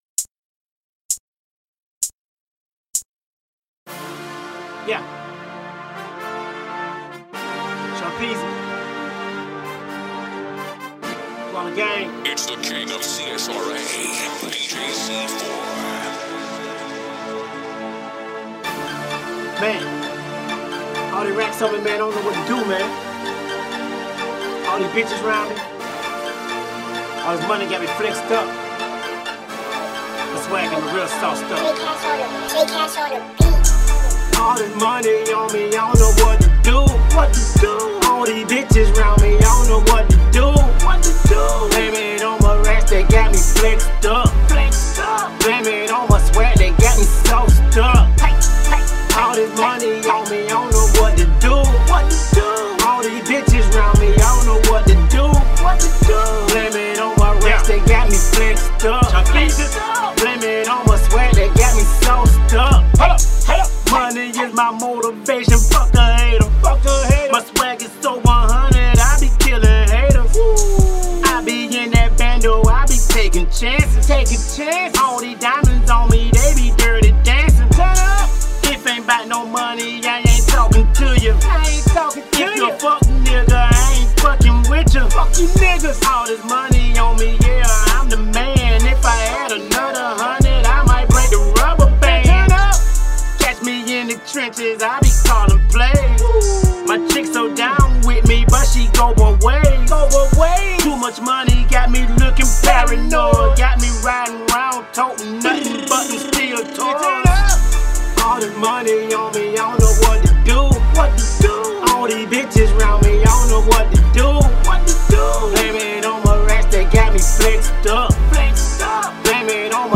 Hiphop
Description : club song